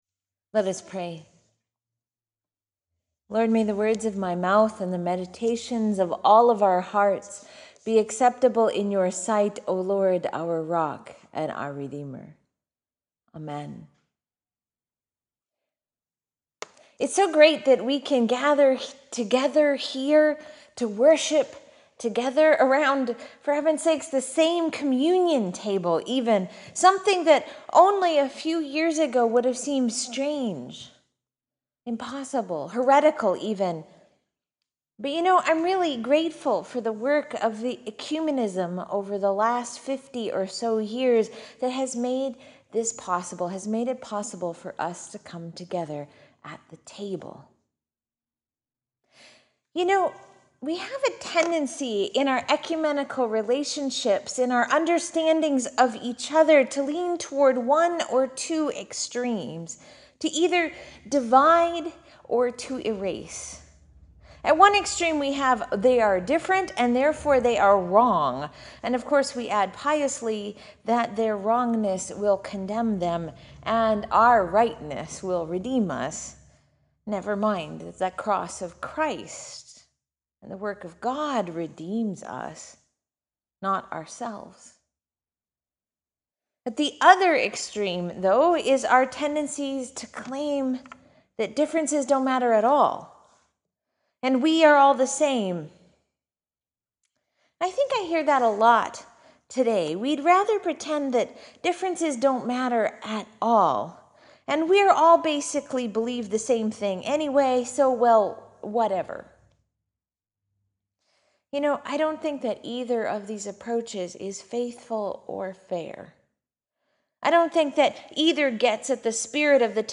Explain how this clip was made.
This recording is a re-recording because this sermon was preached outdoors on the town green. It was a bit loud…)